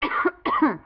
cough1.wav